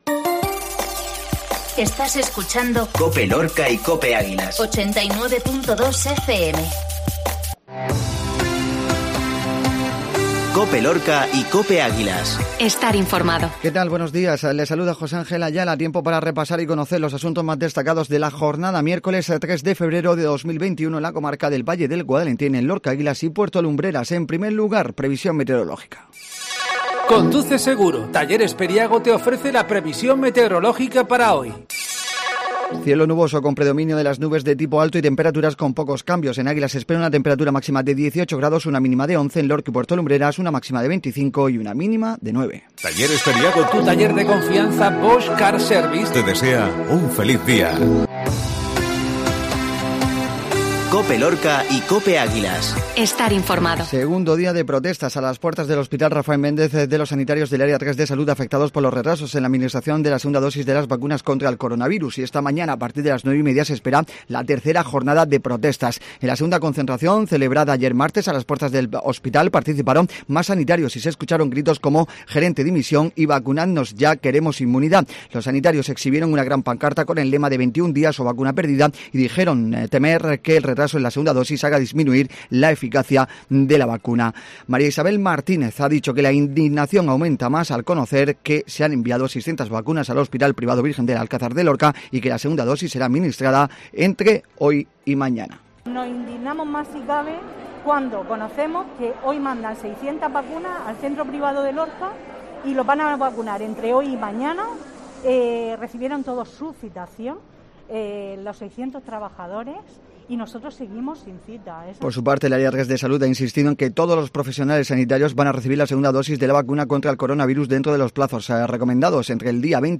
INFORMATIVO MATINAL MIÉRCOLES